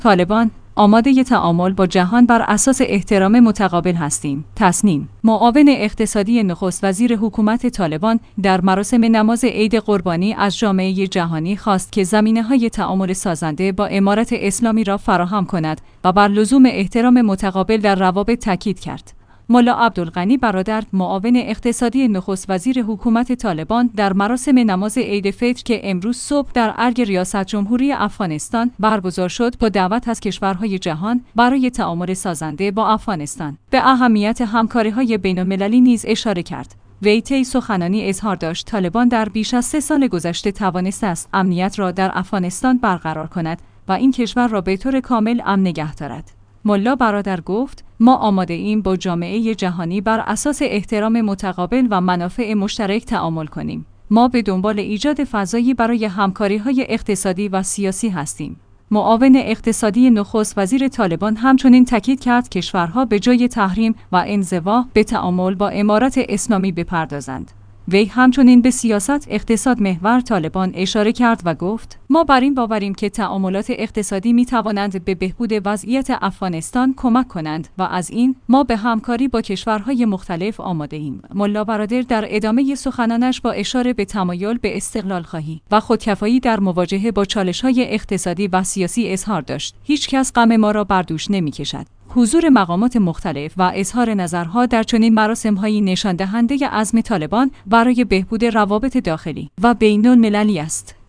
تسنیم/ معاون اقتصادی نخست وزیر حکومت طالبان در مراسم نماز عید قربانی از جامعه جهانی خواست که زمینه‌های تعامل سازنده با امارت اسلامی را فراهم کند و بر لزوم احترام متقابل در روابط تأکید کرد.